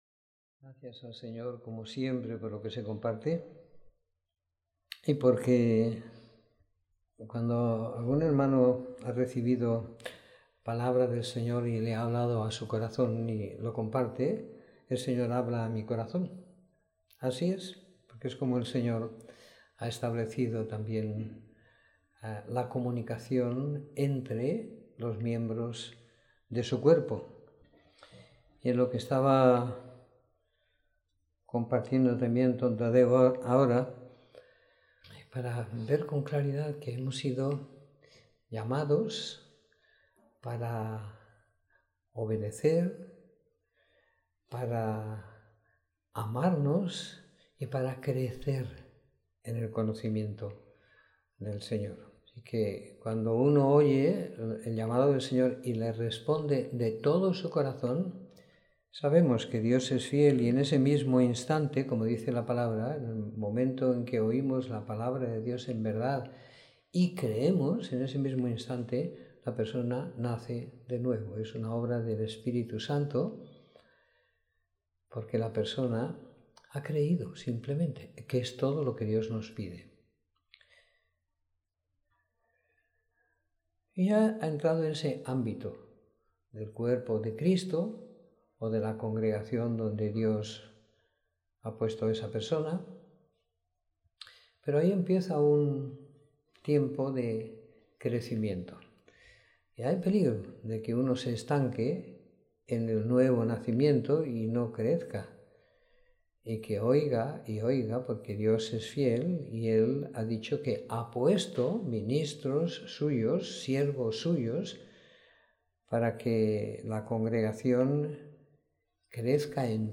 Reunión semanal donde de compartir de la Palabra y la Vida